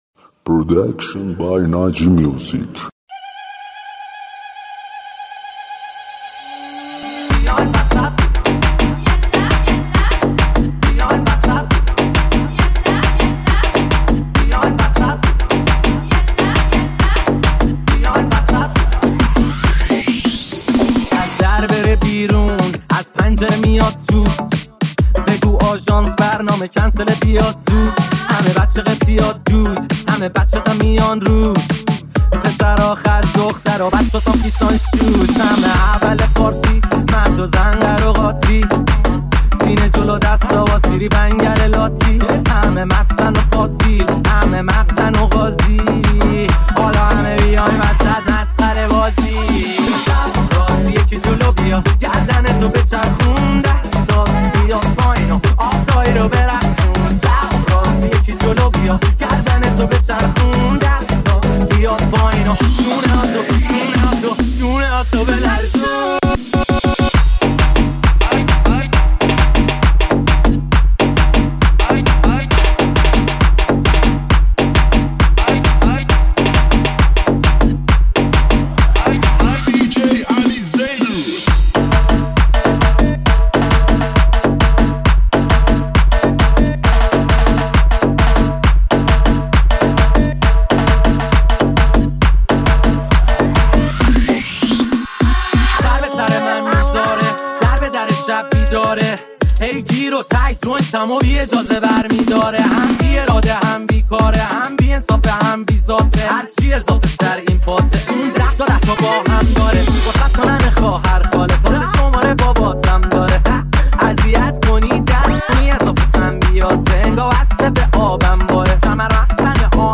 ریمیکس رقصی مخصوص پارتی و عروسی
ریمیکس شاد تریبال